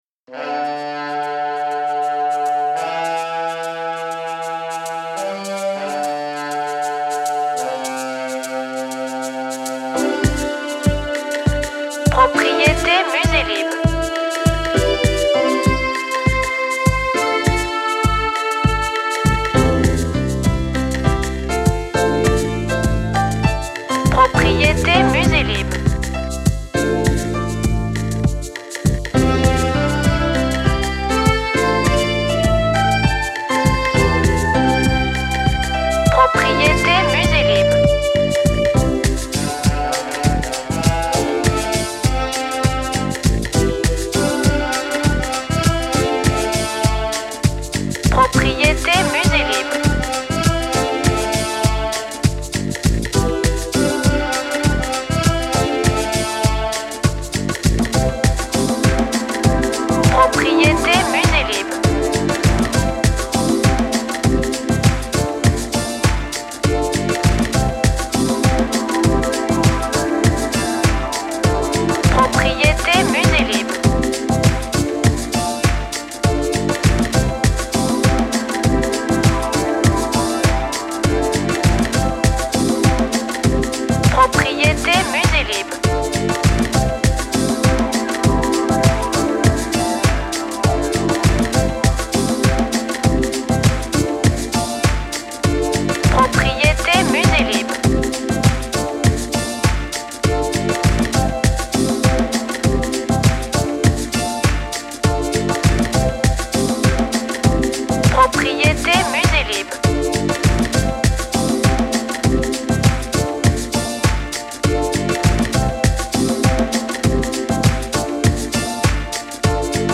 Musique lounge, décontractante et rafraîchissante.